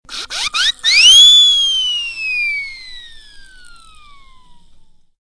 clock12.ogg